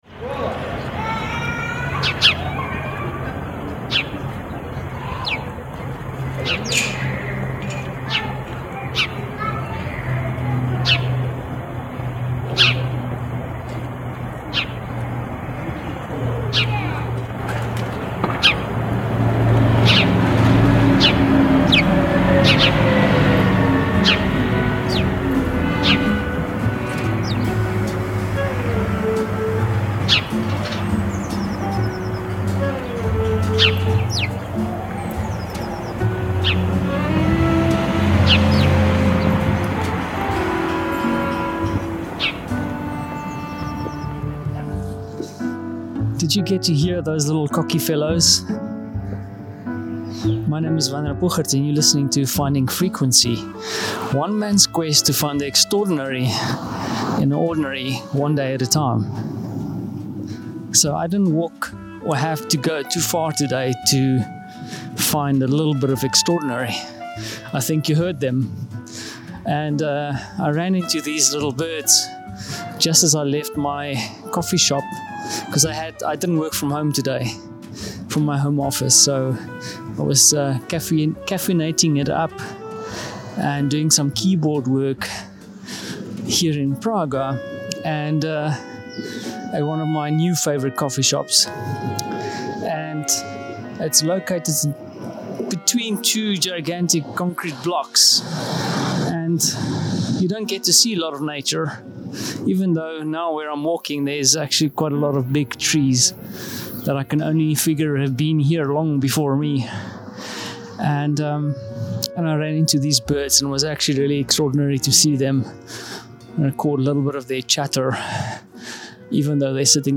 #65 Ran into a few chirpy fellows in the city after a long day trying to figure out some website coffee on a strong caffeine buzz.